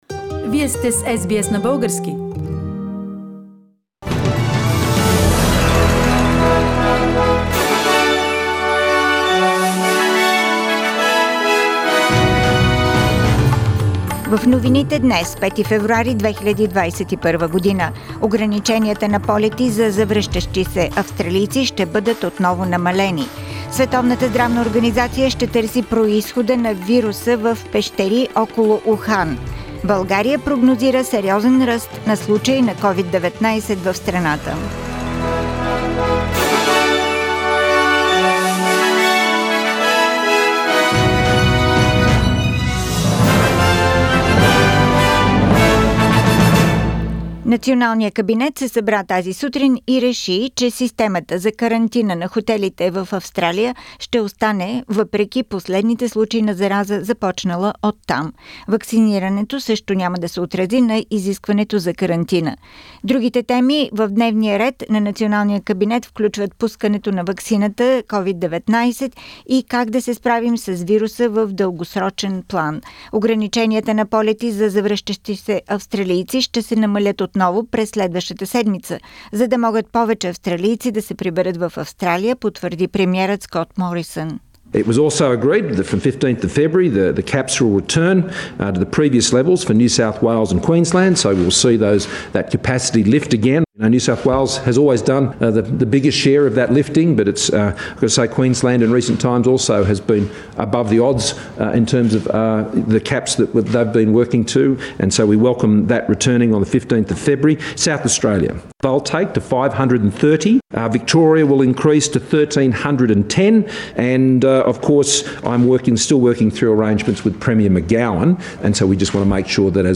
Weekly Bulgarian News – 5th February 2021